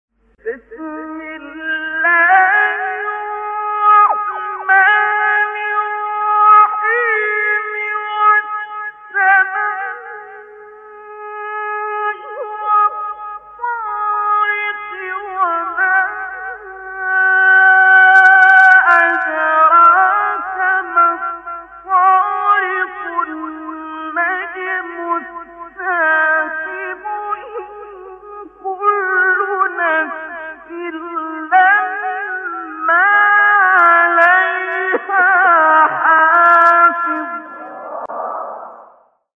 آیات ابتدایی سوره طارق استاد عبدالباسط | نغمات قرآن | دانلود تلاوت قرآن